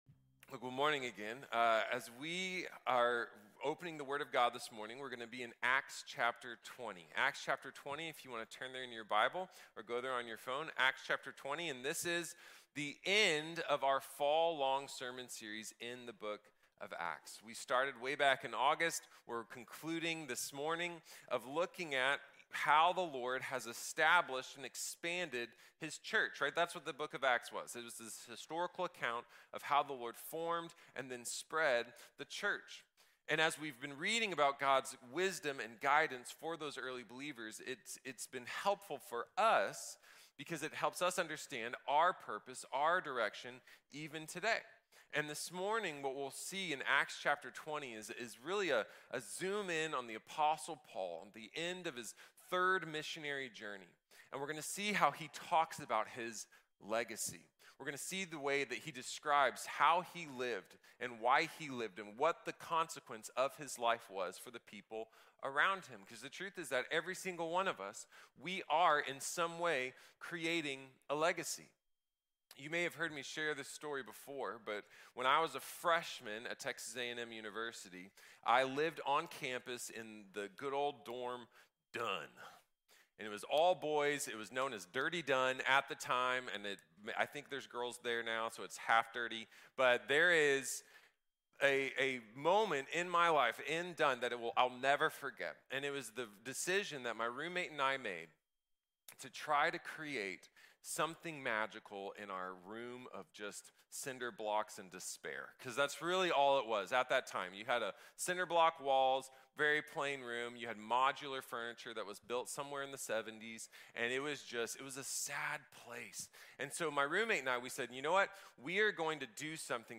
Legado Perdurable | Sermón | Iglesia Bíblica de la Gracia